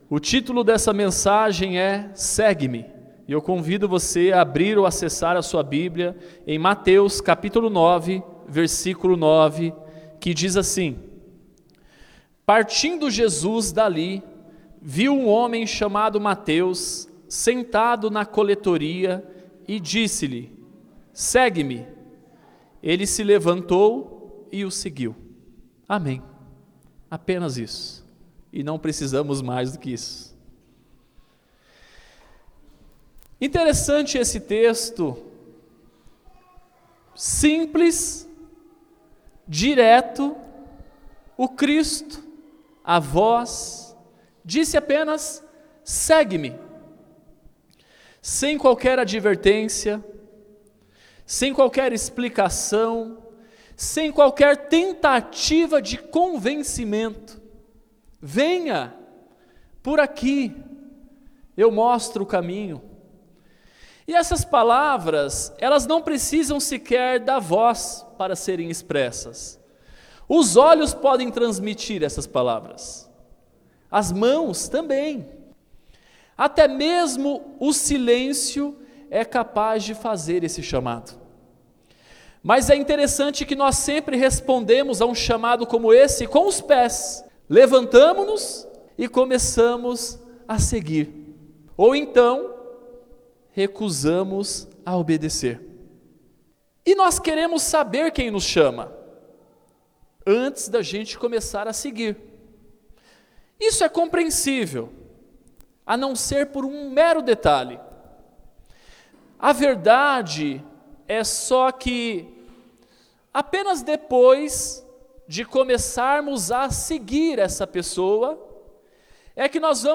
Este episódio pode ter algumas mudanças de áudio devido a reforma que esta ocorrendo em nosso templo.